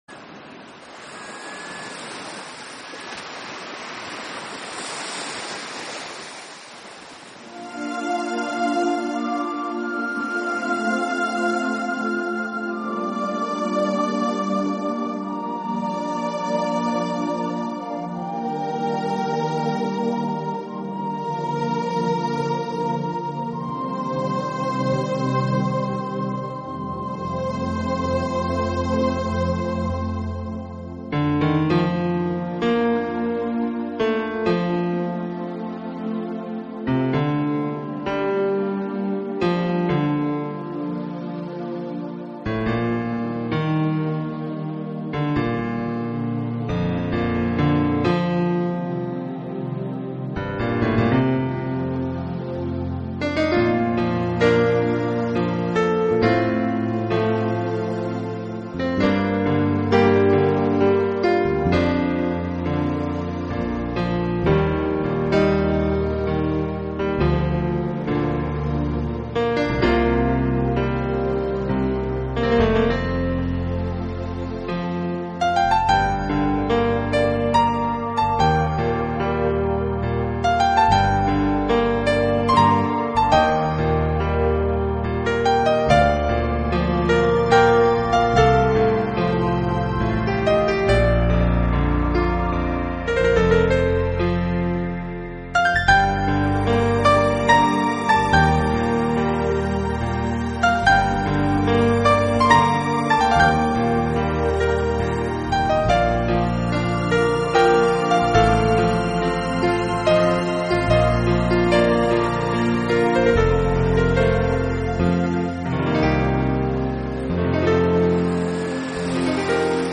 有成就的名人了，他的作品众多，清新流畅、抒情隽永，给人留下很深的印象。